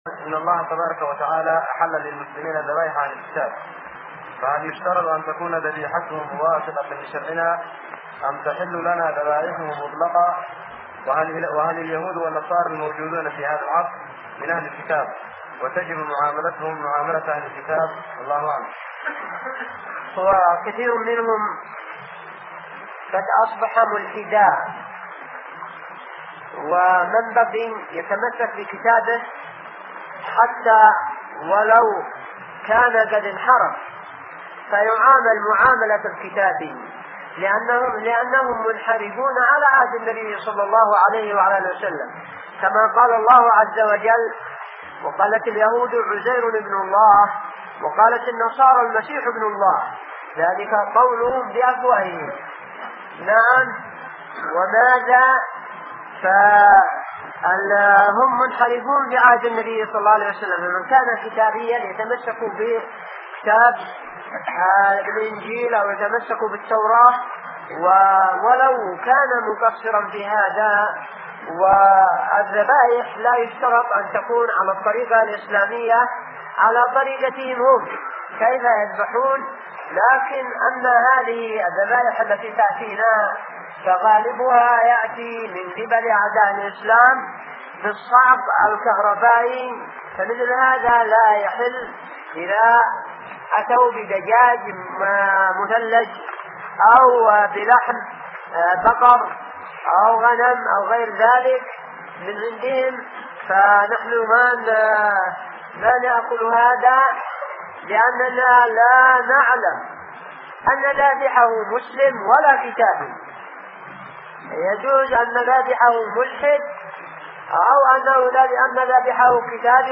فتاوى الشيخ